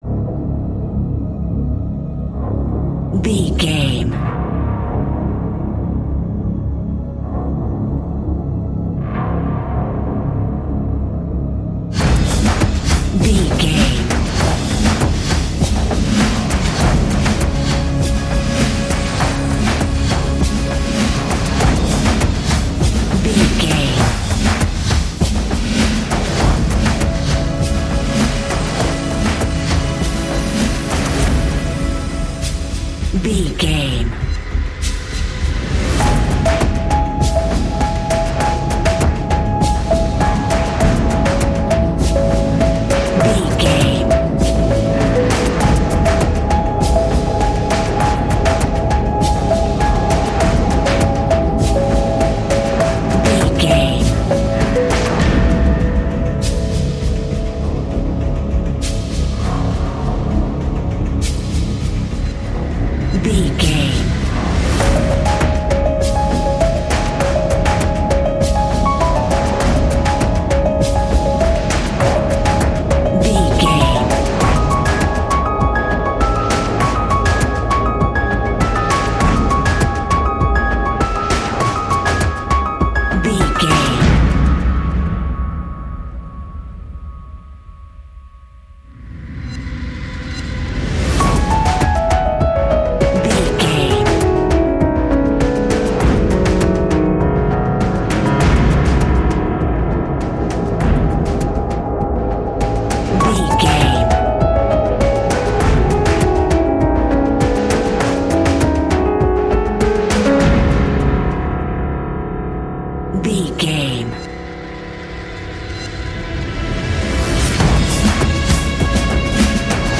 Epic / Action
Fast paced
In-crescendo
Thriller
Ionian/Major
C♯
industrial
dark ambient
EBM
drone
synths
Krautrock